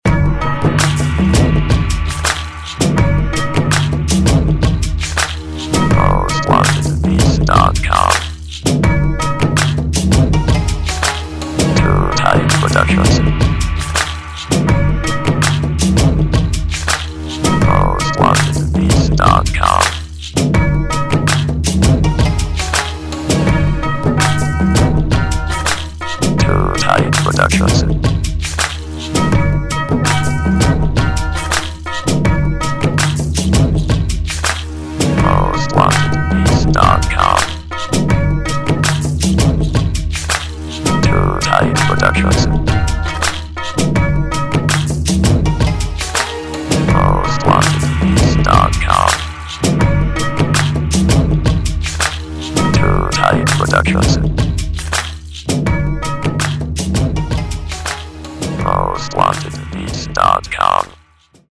HIP HOP INSTRUMENTAL